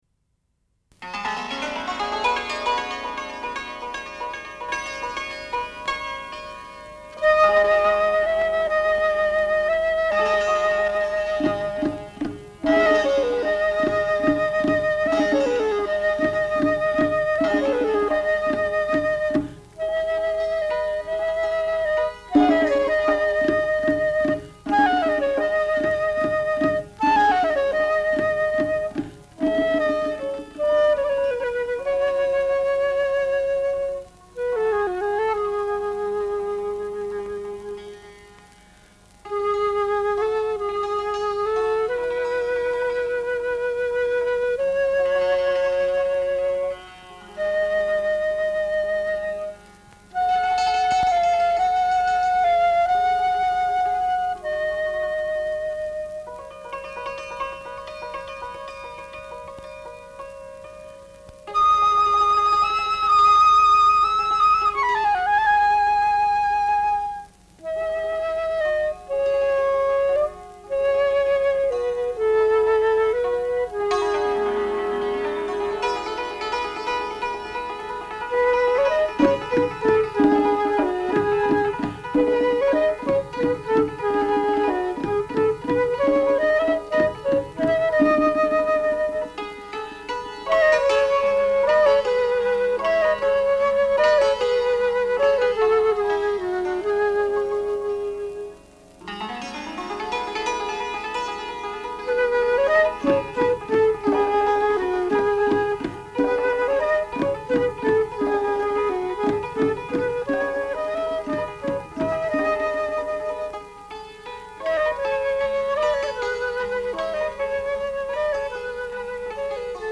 فلوت
سه تار
سنتور
تمبک